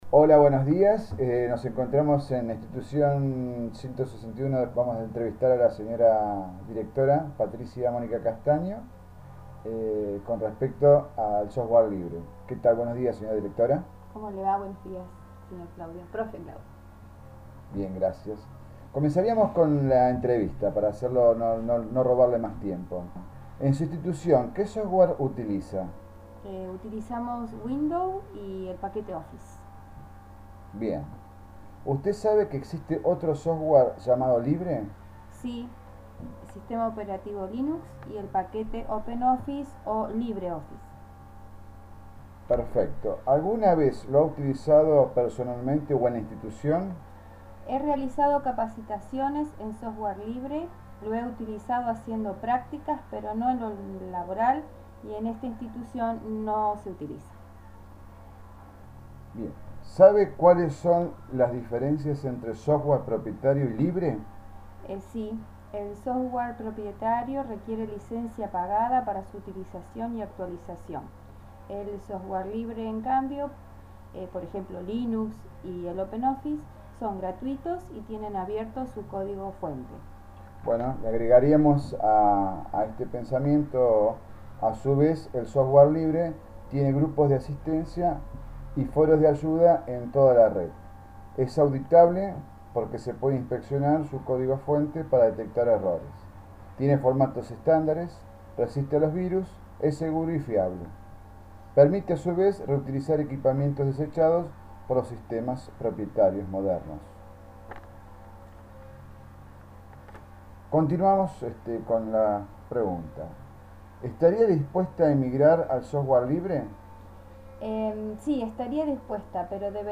3-entrevista.mp3